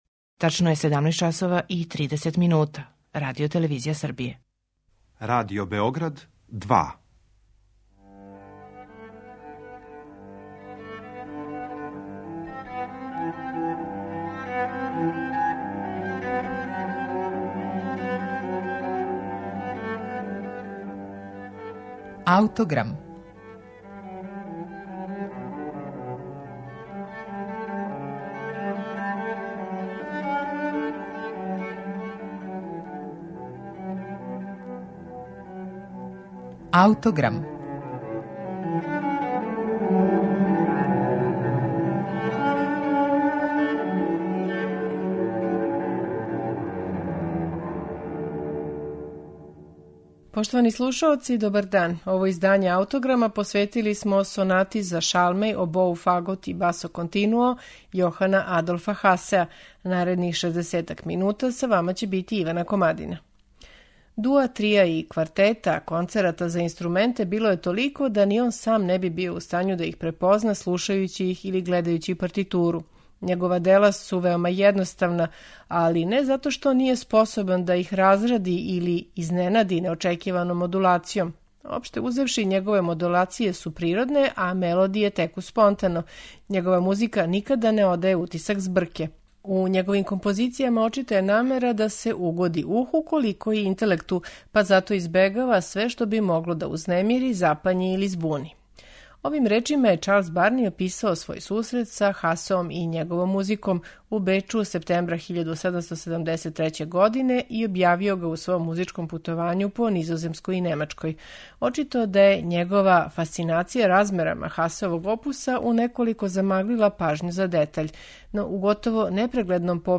за шалмеј, обоу, фагот и басо континуо